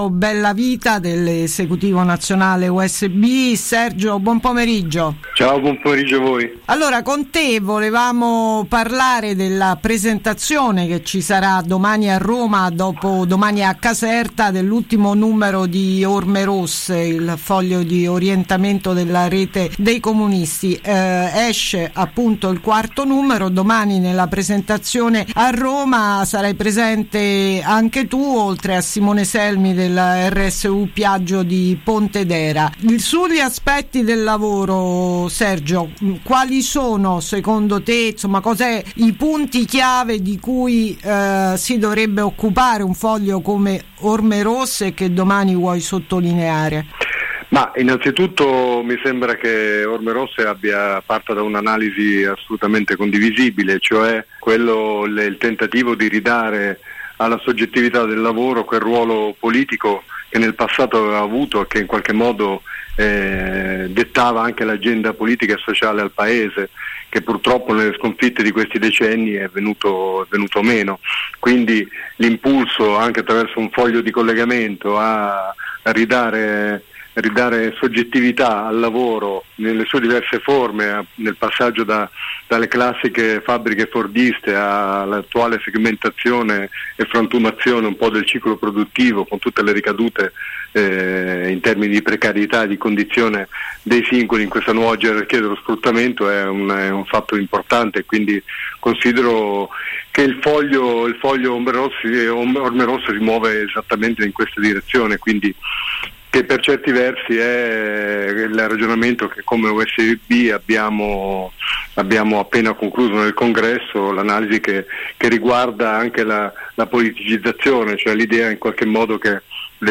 Orme Rosse – Intervista